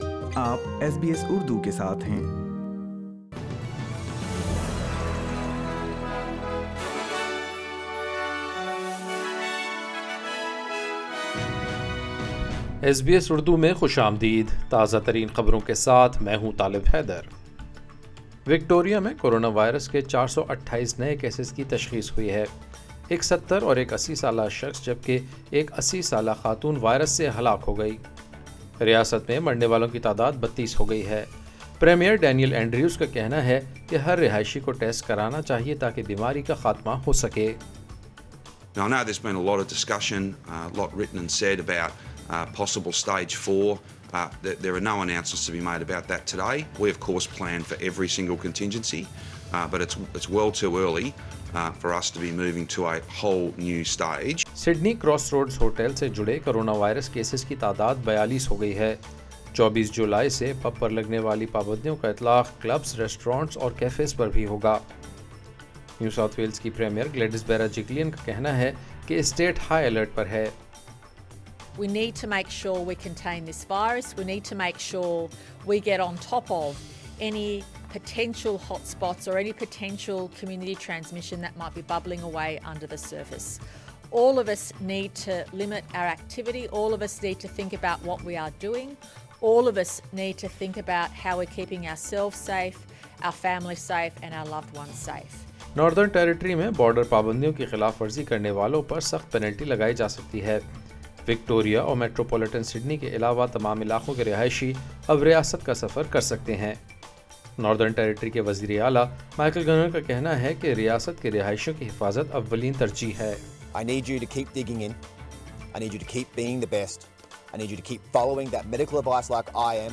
ایس بی ایس اردو خبریں 17 جولائی 2020